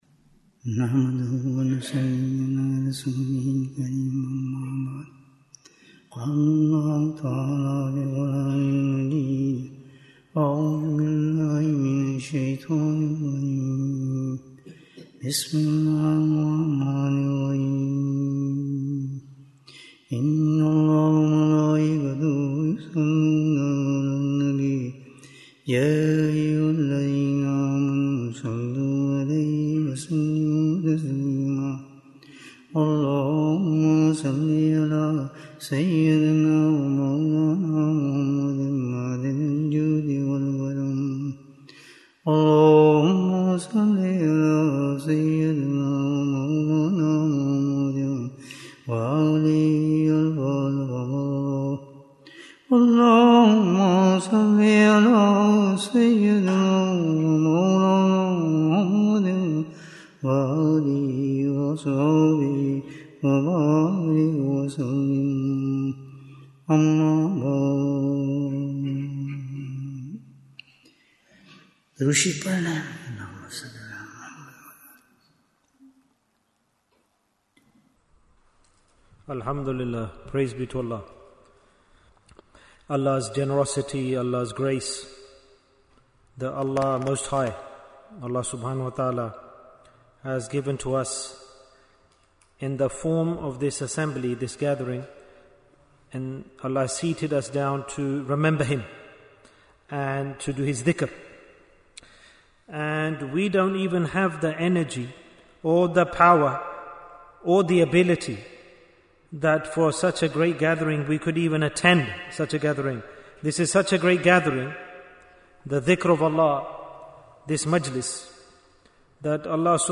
How Can We Protect Our Iman? Bayan, 55 minutes17th July, 2025